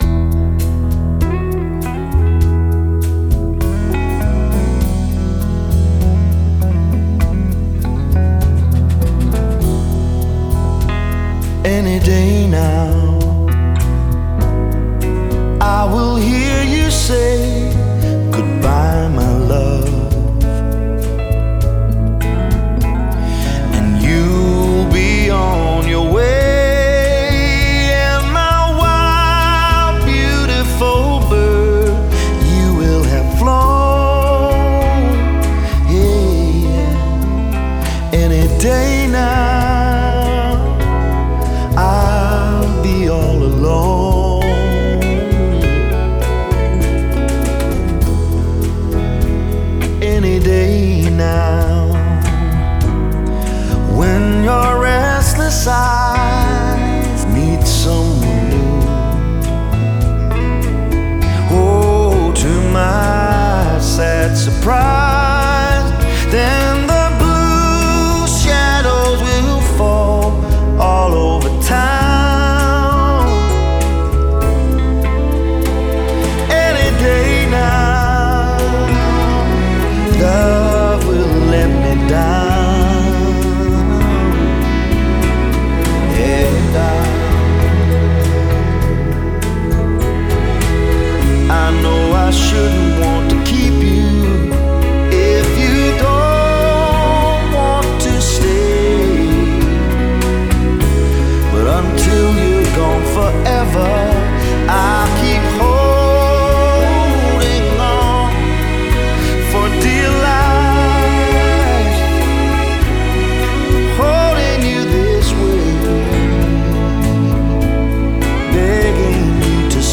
Genre: Pop/Rock, Blue-eyed Soul